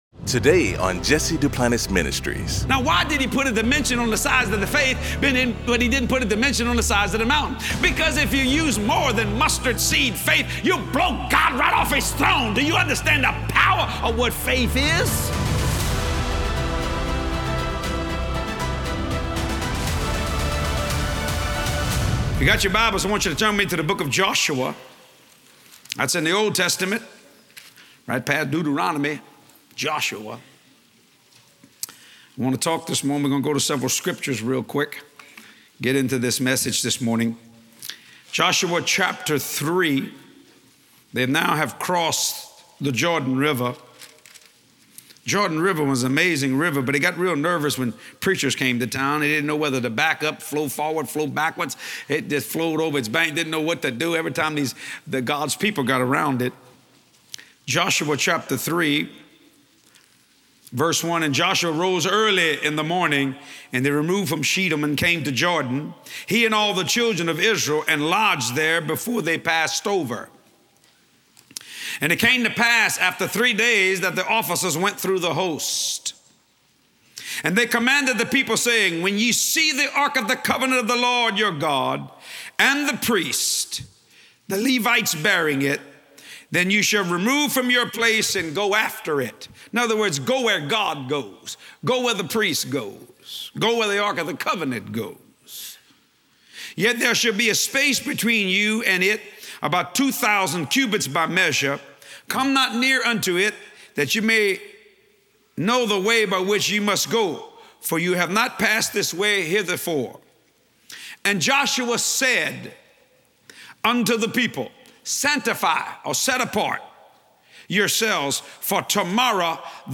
In this faith-filled message